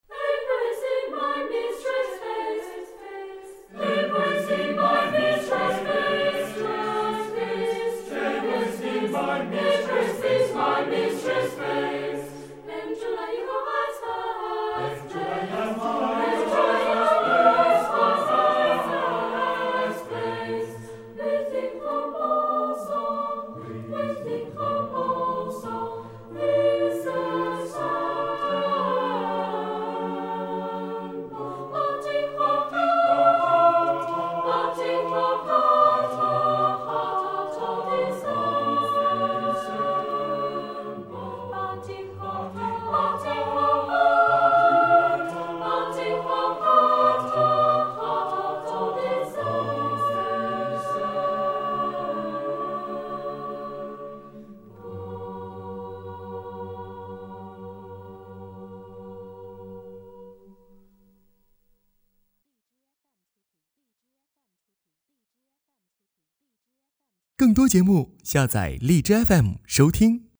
录音地址:石碑胡同中国唱片社录音棚
【欧洲牧歌部分】